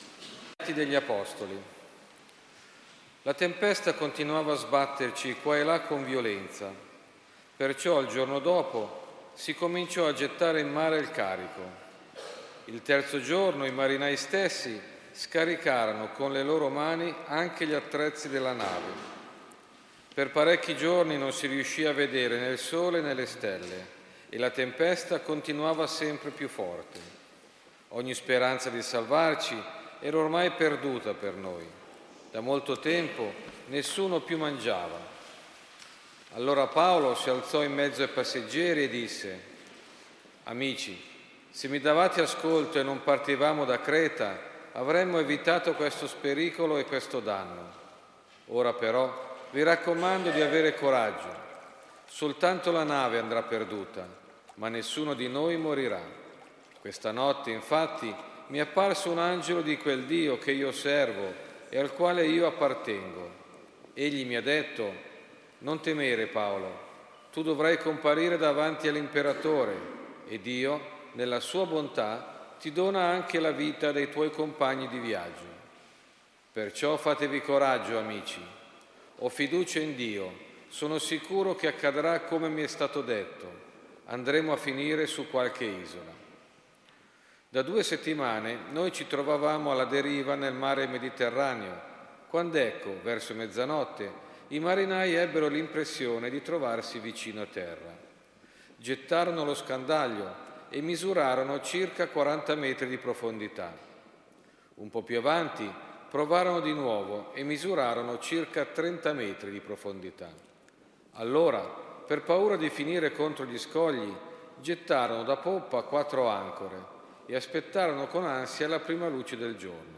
Audio della Liturgia della Parola della Celebrazione Ecumenica del 24 gennaio 2020 a Germignaga